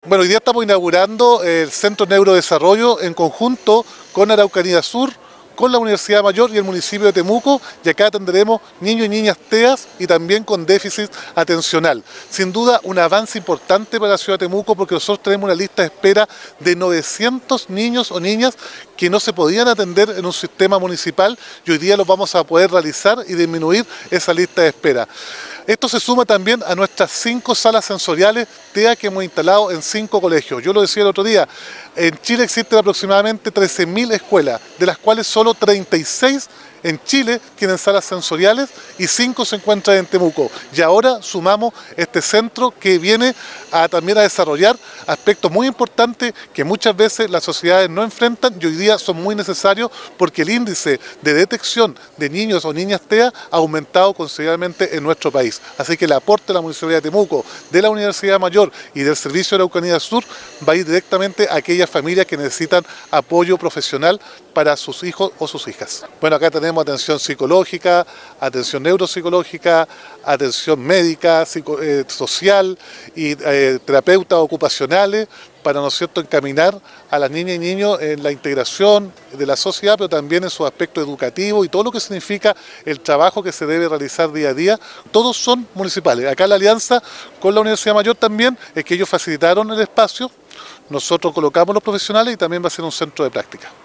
Roberto-Neira-alcalde-de-Temuco-Centro-Neurologico.mp3